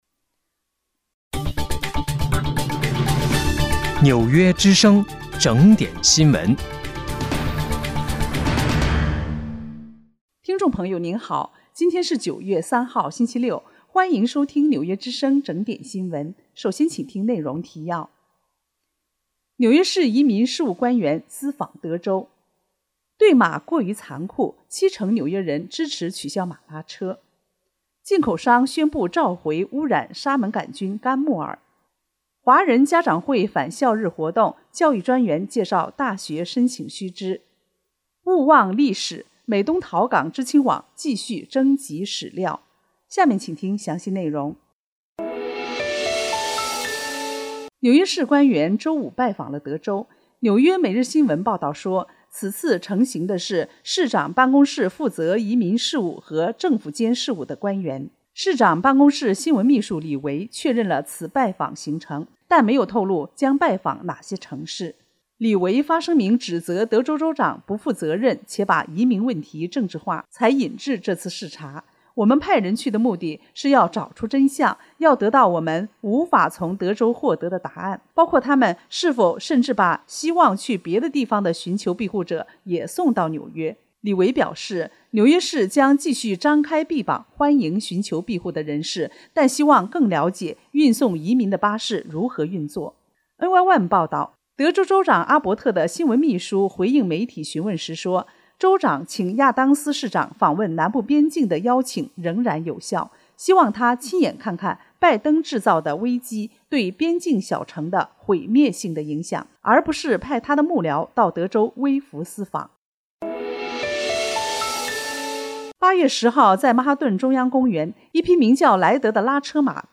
9月3日（星期六）纽约整点新闻